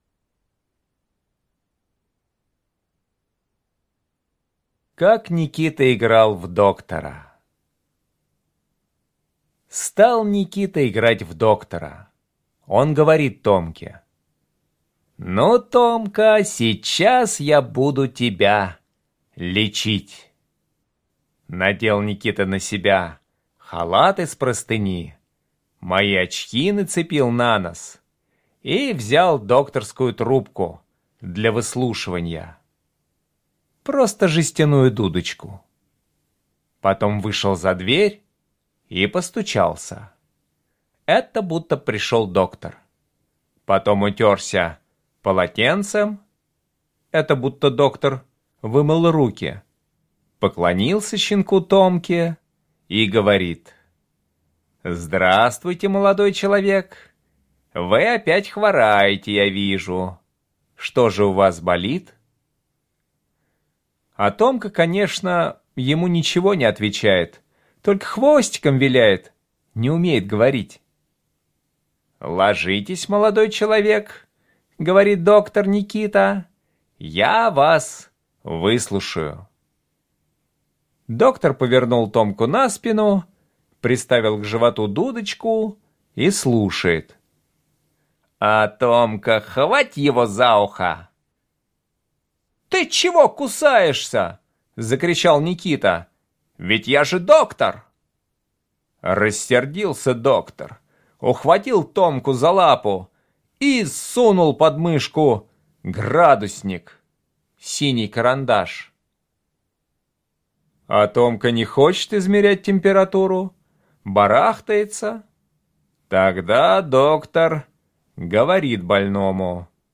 Аудиорассказ «Как Никита играл в доктора»